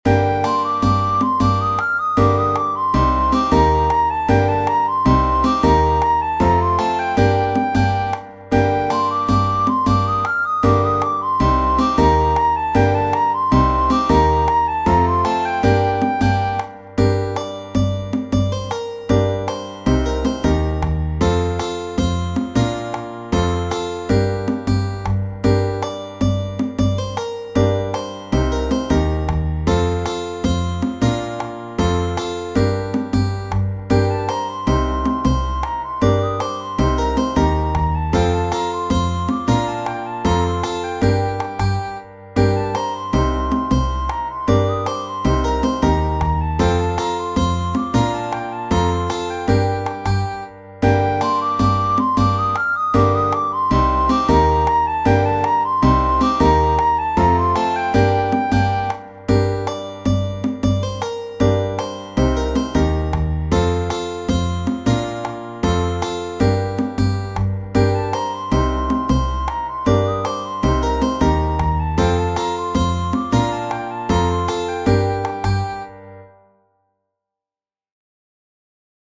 Tradizionale Genere: Folk "Kalesh Kate" è una canzone che appartiene al repertorio folk della Bulgaria.